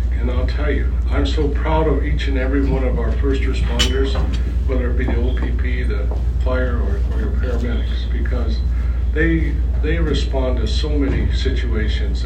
Quinte West Mayor Jim Harrison gave his annual State of the City Address Friday at the Ramada Inn in Trenton, an event hosted by the Quinte West Chamber of Commerce.